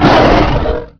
pain.wav